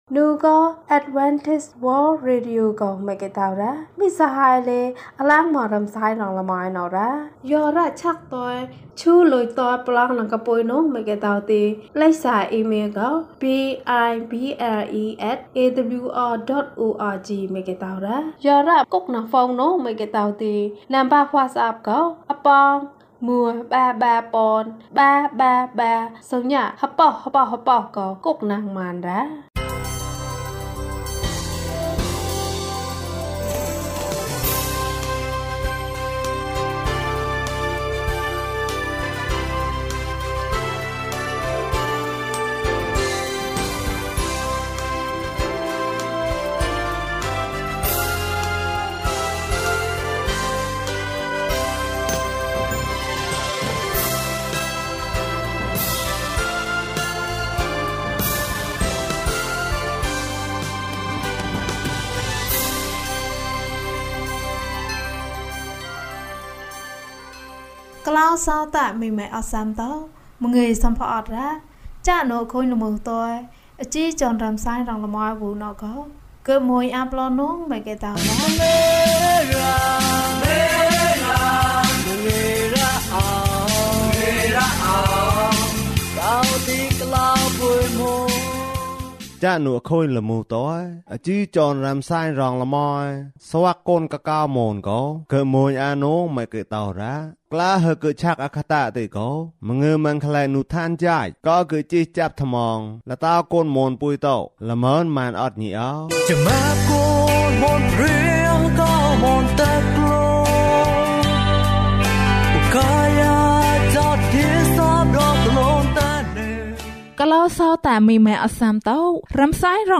ဘုရားသခင်သည် ချစ်ခြင်းမေတ္တာဖြစ်သည်။၀၁ ကျန်းမာခြင်းအကြောင်းအရာ။ ဓမ္မသီချင်း။ တရားဒေသနာ။